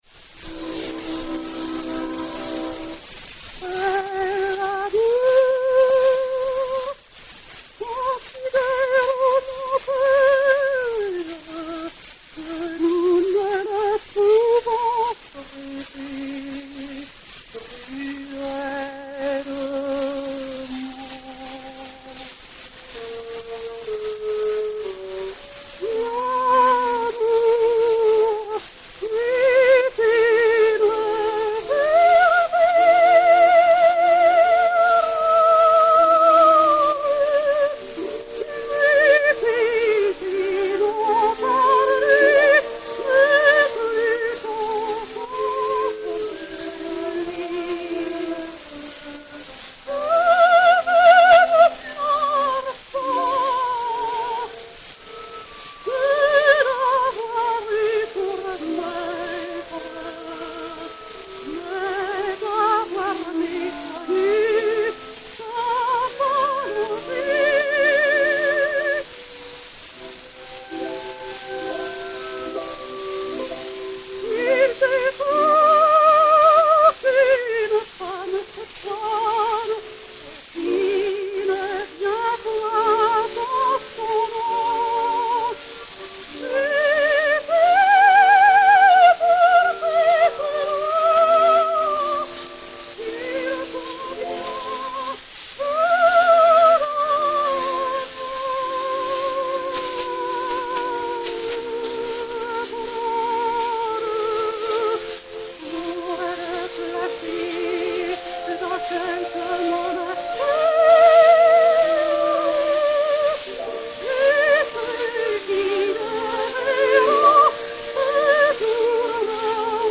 enr. à New York le 15 février 1912